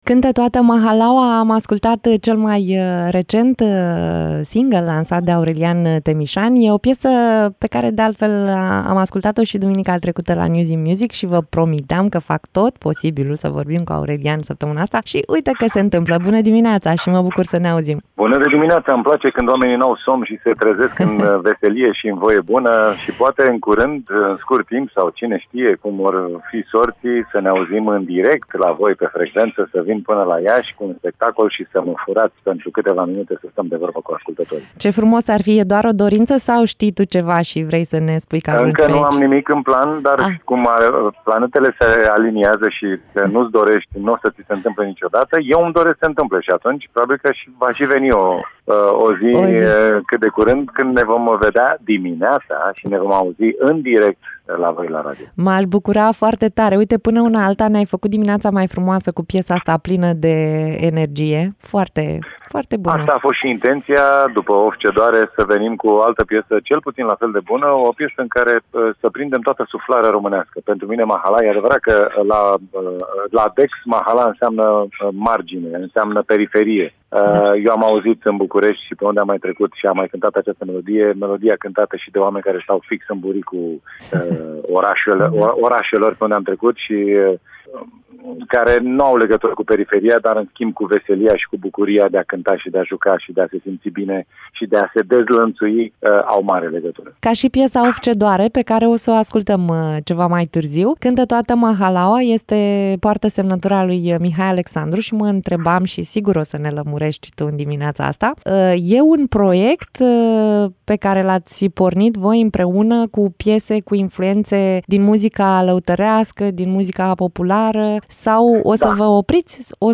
Aurelian Temişan în direct la Radio Iaşi.
Ascultați mai jos interviul integral și urmăriți videoclipul piesei „Cântă toată mahalaua”!
16-Iun-Interviu-Temisan.mp3